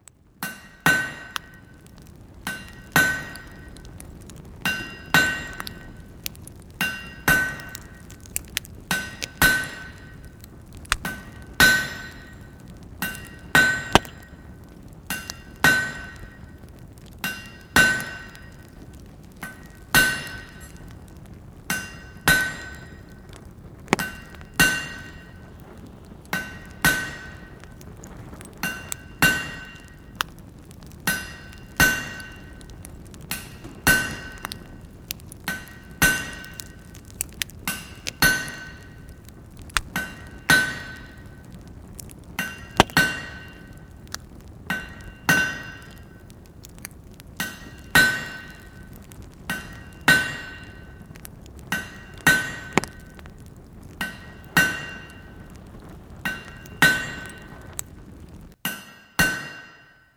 KFjmjVHe75z_forge.wav